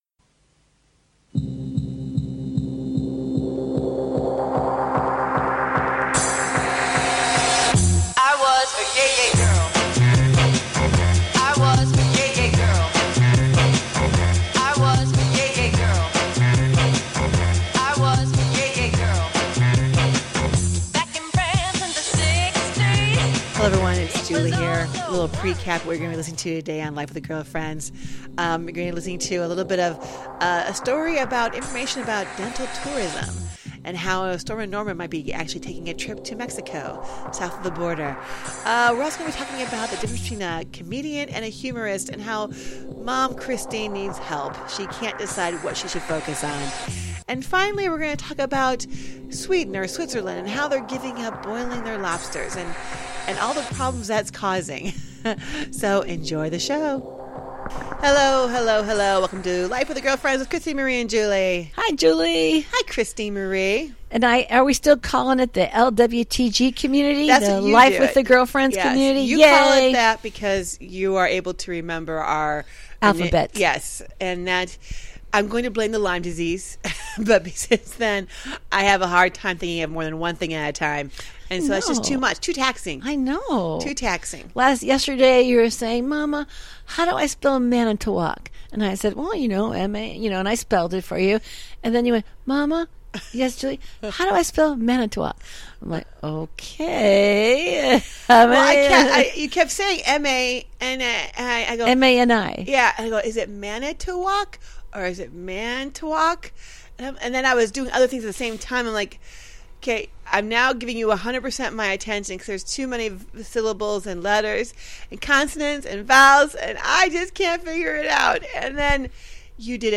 They welcome a wide range of guest to their den for some juicy conversation.